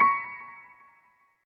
piano89.ogg